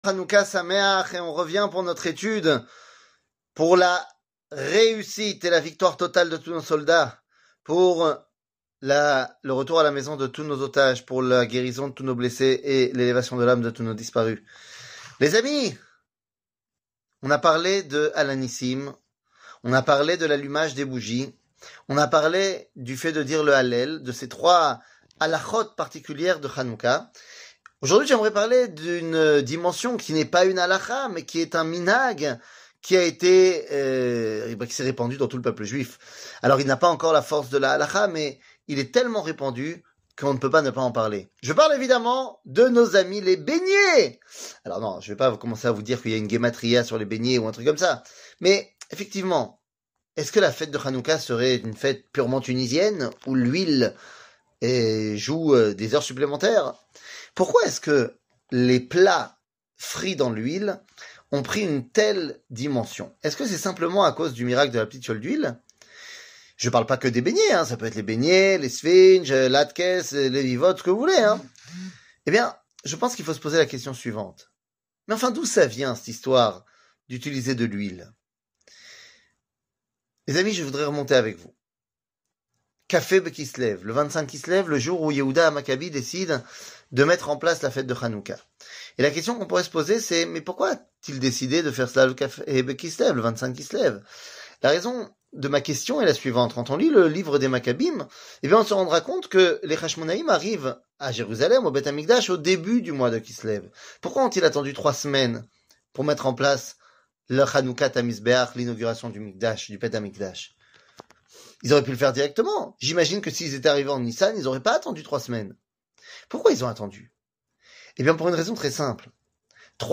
קטגוריה Special Hanouka 4 00:04:50 Special Hanouka 4 שיעור מ 13 דצמבר 2023 04MIN הורדה בקובץ אודיו MP3